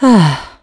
Valance-Vox_Sigh2.wav